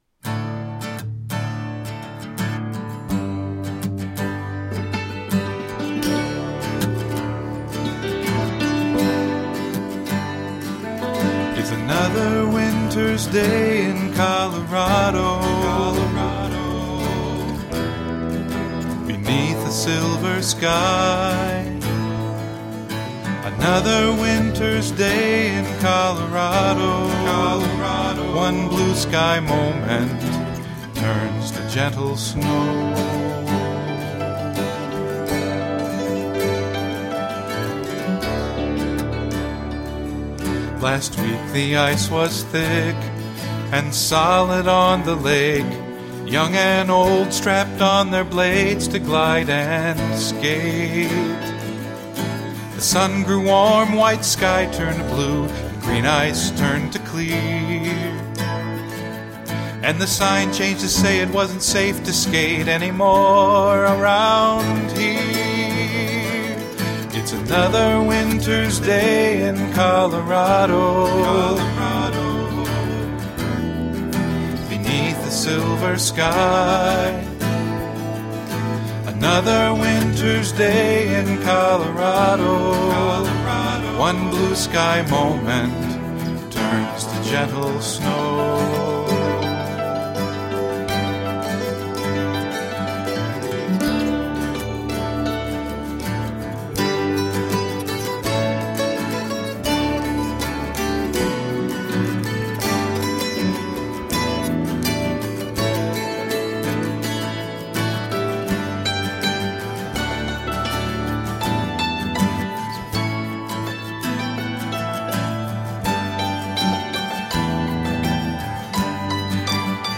Smile-inducing, toe-tapping folkgrass.
Tagged as: New Age, Folk, World, Country, Hammered Dulcimer